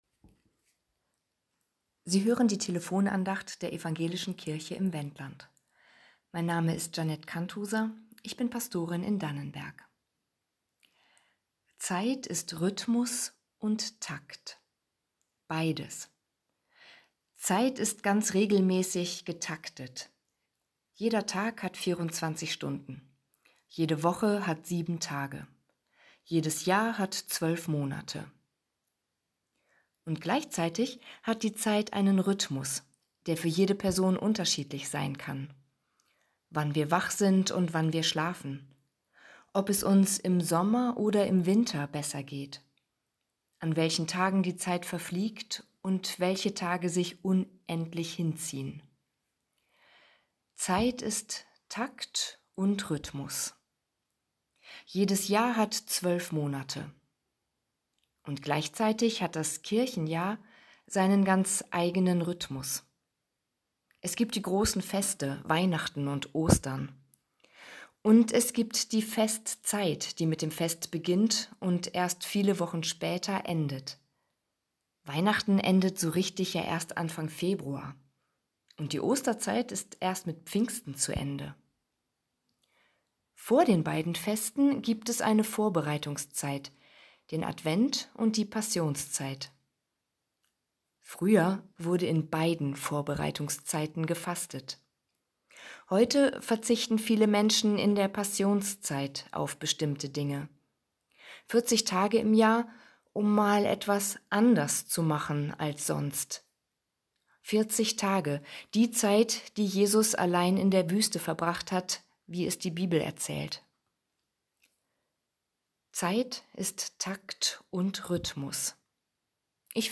Zeittakte ~ Telefon-Andachten des ev.-luth. Kirchenkreises Lüchow-Dannenberg Podcast